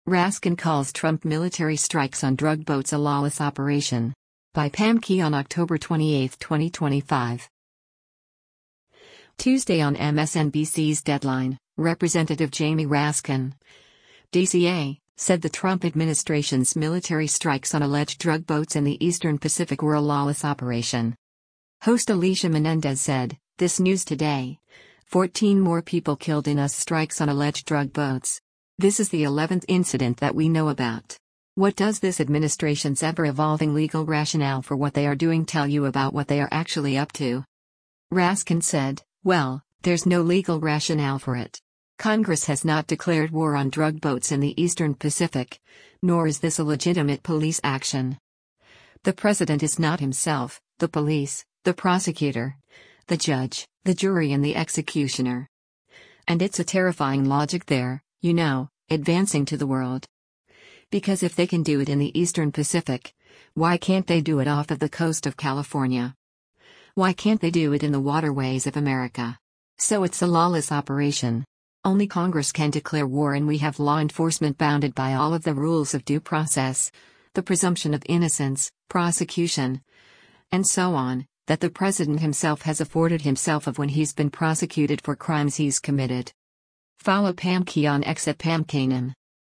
Tuesday on MSNBC’s “Deadline,” Rep. Jamie Raskin (D-CA) said the Trump administration’s military strikes on alleged drug boats in the eastern Pacific were a “lawless operation.”